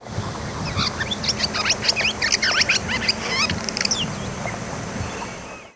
Barn Swallow diurnal flight calls
Song from bird in spring migration flight.